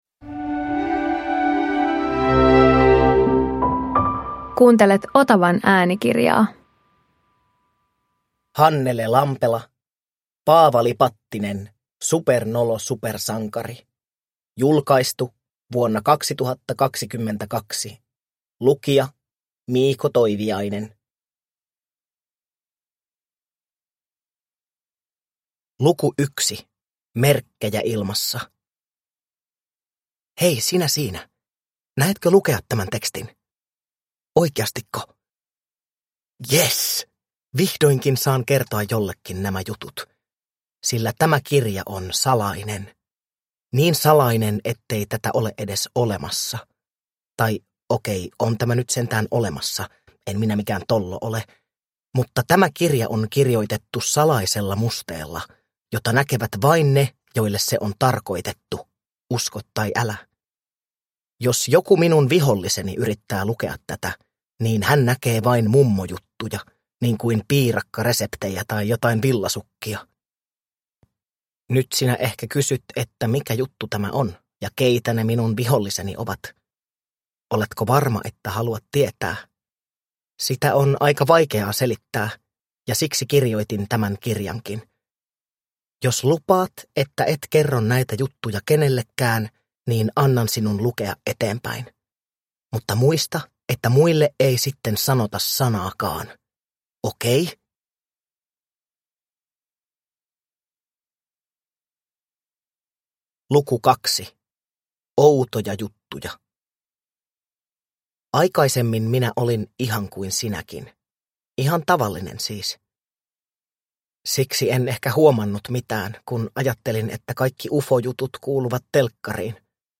Paavali Pattinen, supernolo supersankari – Ljudbok